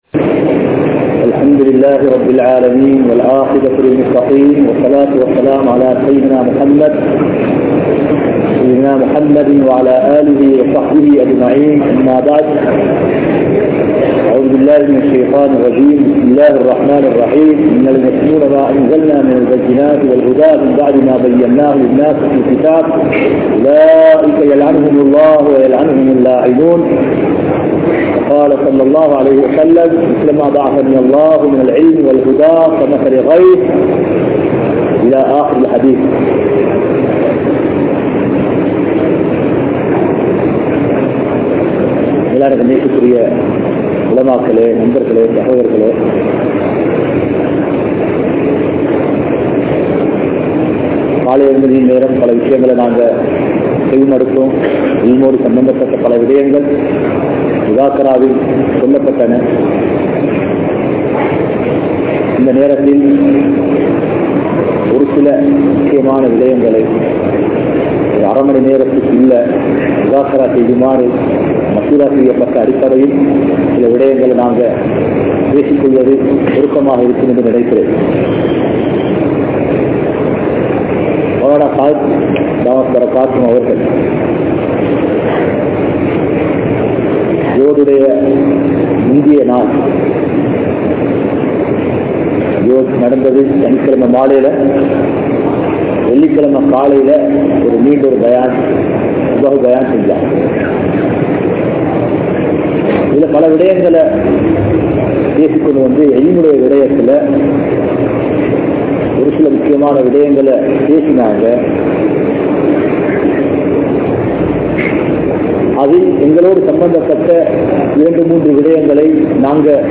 Dhauwath Entraal Enna? (தஃவத் என்றால் என்ன?) | Audio Bayans | All Ceylon Muslim Youth Community | Addalaichenai